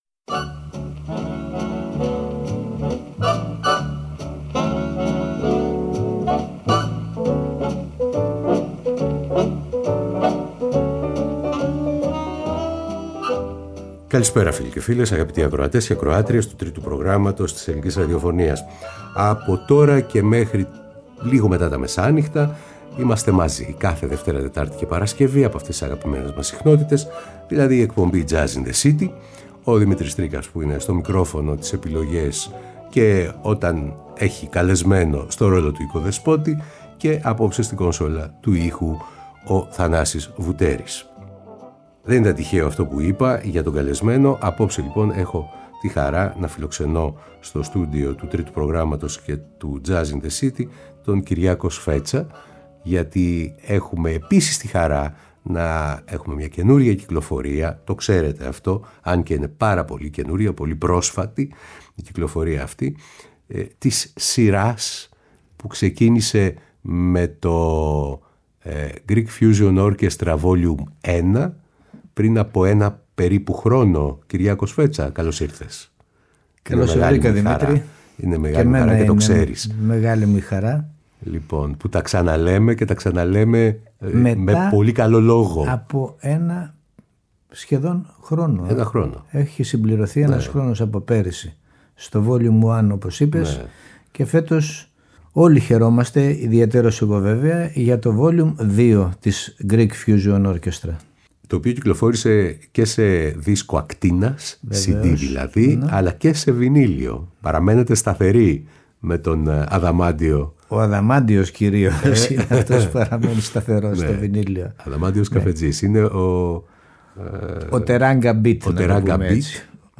Ζωντανά στο στούντιο ο Κυριάκος Σφέτσας με αφορμή το εξαιρετικό Greek Fusion Orchestra vol. 2.
Τζαζ